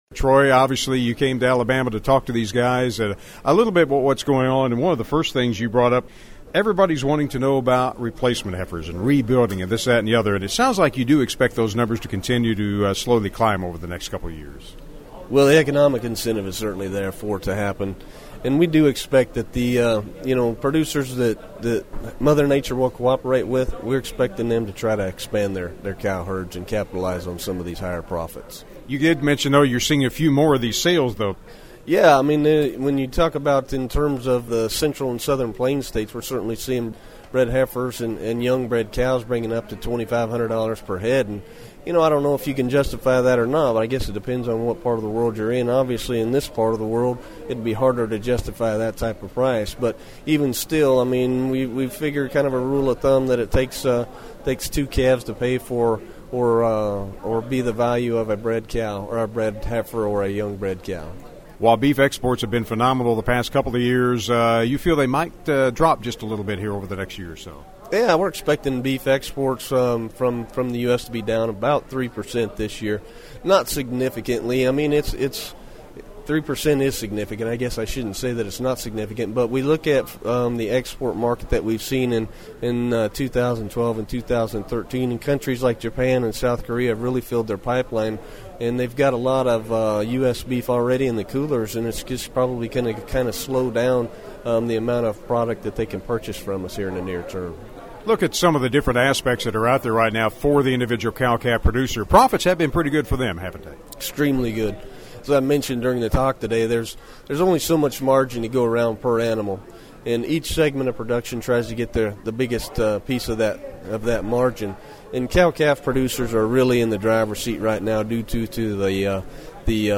I caught up with him afterwards and we discussed expansion, feed costs and of course their projections for cattle prices.